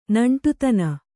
♪ naṇṭutana